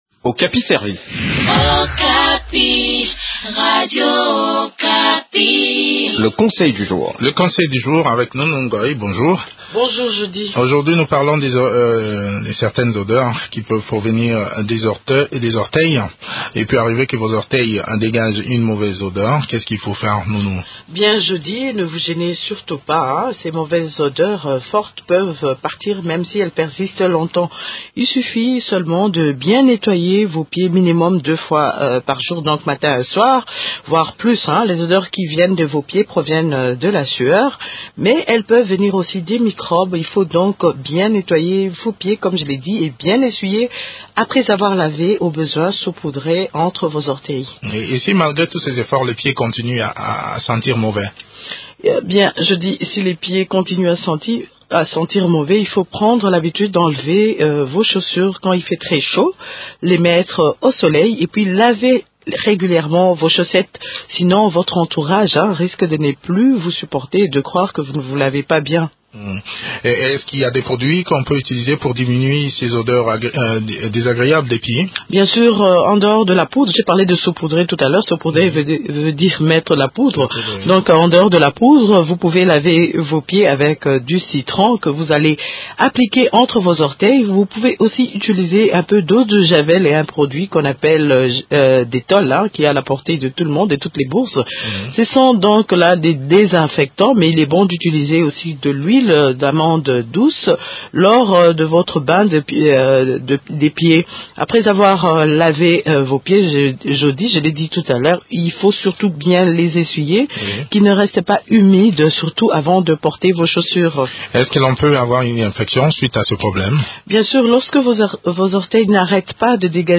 Des astuces dans cet entretien